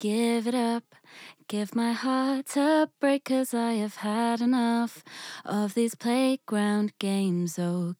とりあえずかけてみると高域のくもりが無くなり、分かりやすく音が良くなる感じがあります。
こうすることで、大体はマイクノイズを軽減する事が出来ながらもOTTの良さを音に反映させる事が出来ます。